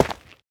Minecraft Version Minecraft Version latest Latest Release | Latest Snapshot latest / assets / minecraft / sounds / block / basalt / break2.ogg Compare With Compare With Latest Release | Latest Snapshot
break2.ogg